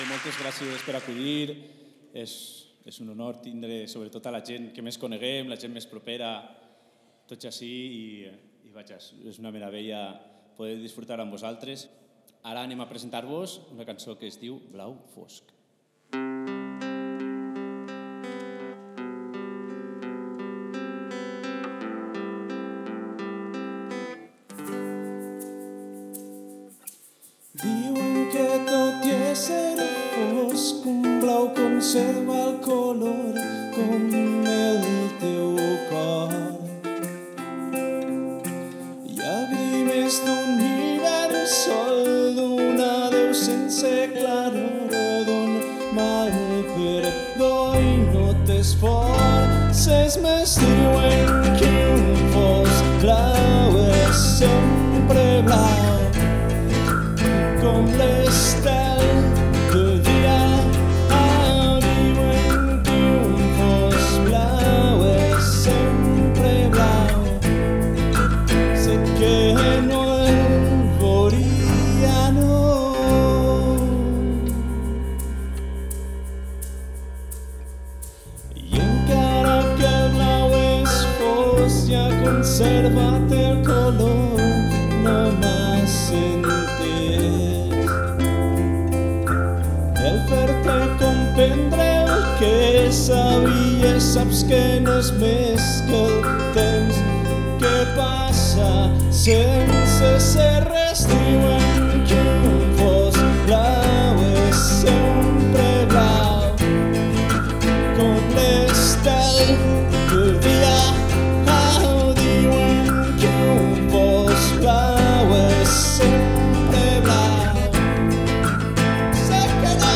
El 24 de març de 2012 a l'Auditori d’Agullent
van enregistrar un disc en acústic en directe